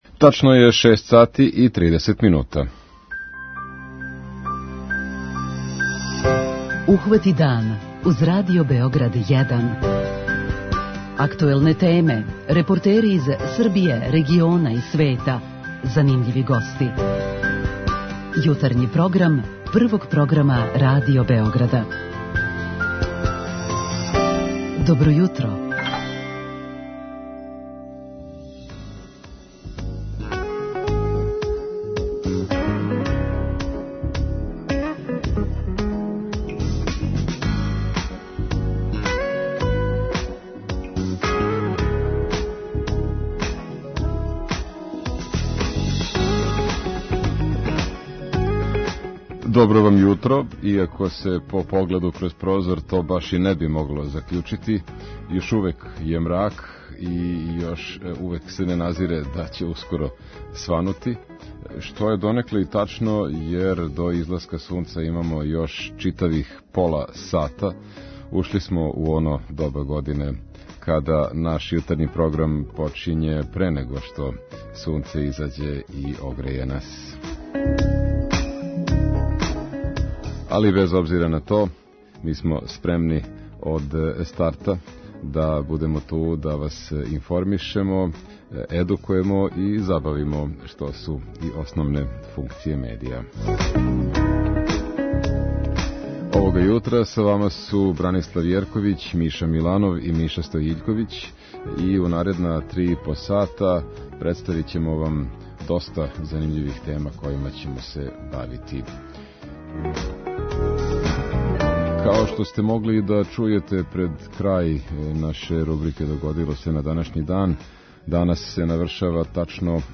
Питаћемо слушаоце како реагују на ову одлуки и шта мисле о томе колики су јој домети. Чућемо и извештај са једног скупа на којем су изнете чињенице о утицају пандемије на сиромаштво.
преузми : 37.78 MB Ухвати дан Autor: Група аутора Јутарњи програм Радио Београда 1!